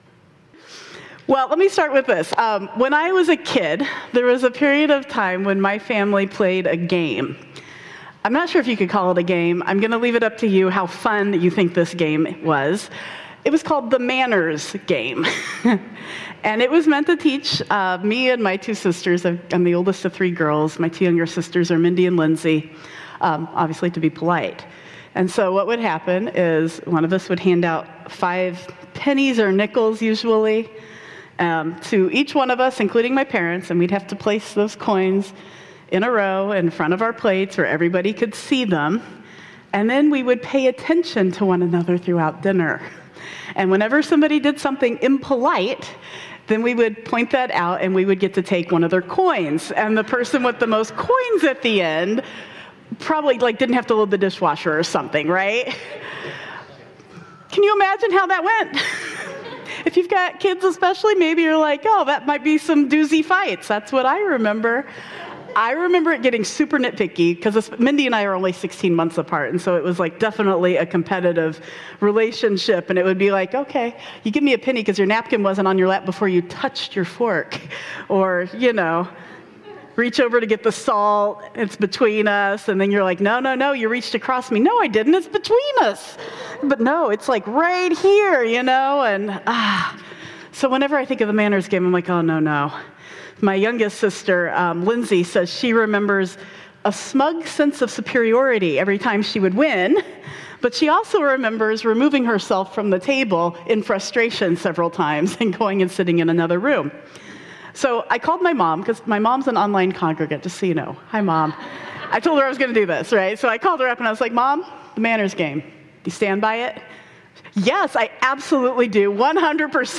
19 Oct 2025 | Sermon On the Mount: Spiritual Practices